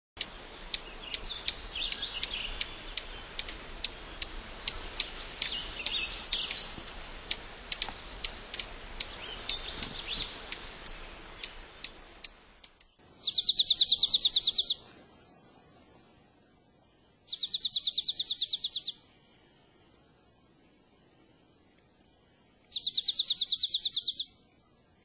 Dark-eyed Junco
Junco hyemalis
You'll often hear their high chip notes, given almost absent-mindedly while foraging, or intensifying as they take short, low flights through cover.
Bird Sound
Does not sing. Calls buzzy; also sharp chips. Wings of adult male make a high, buzzy trill.
Dark-eyedJunco.mp3